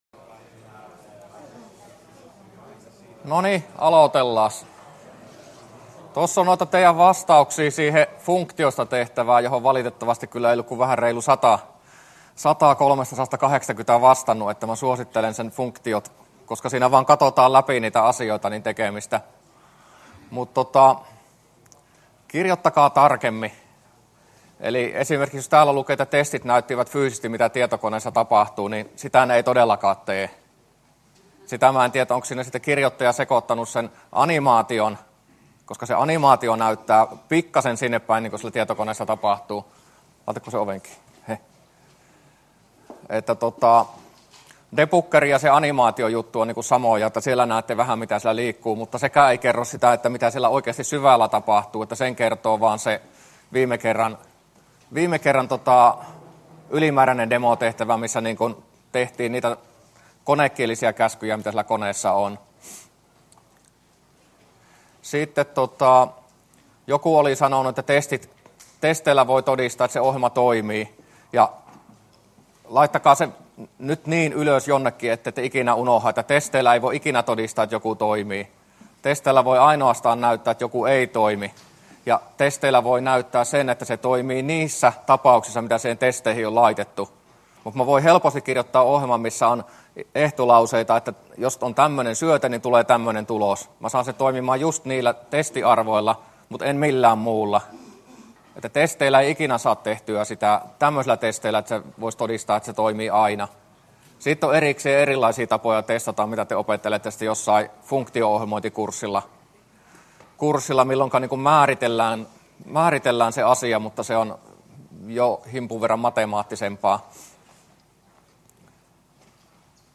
luento09a